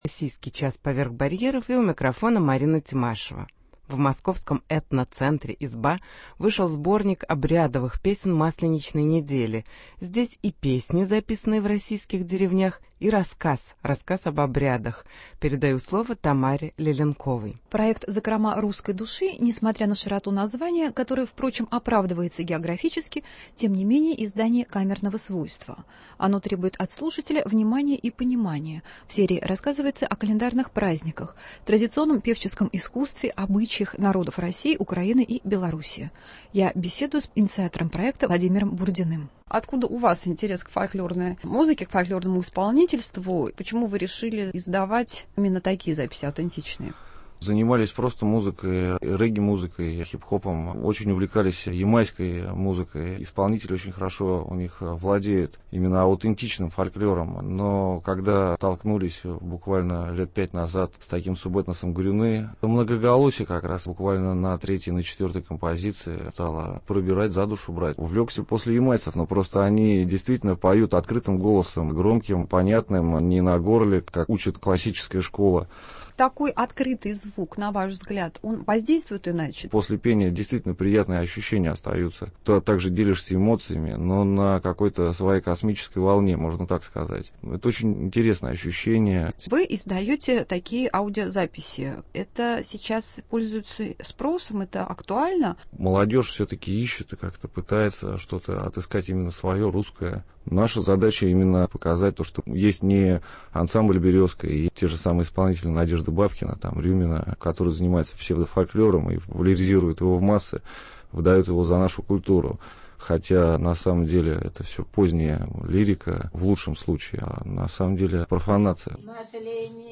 Обрядовые песни масленичной недели